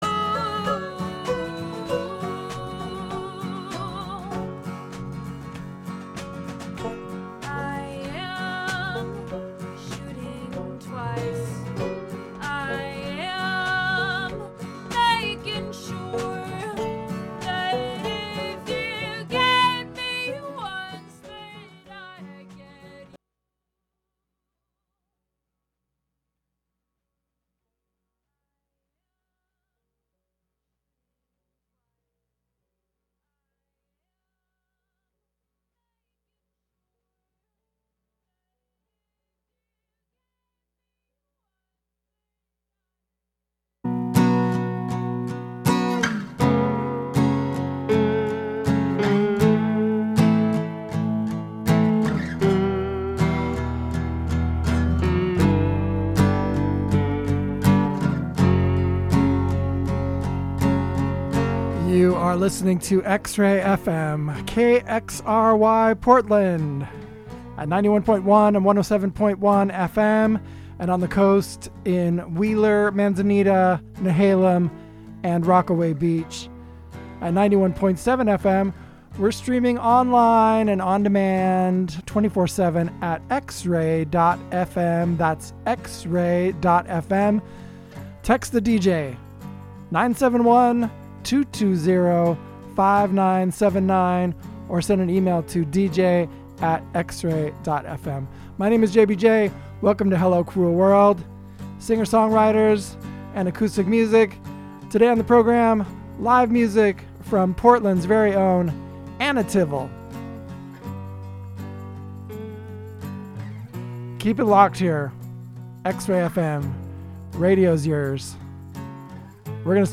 Hello Cruel World brings the worlds of singer/songwriters and acoustic music to you every Thursday from 3-4pm with conversations and in-studio performances as often as possible.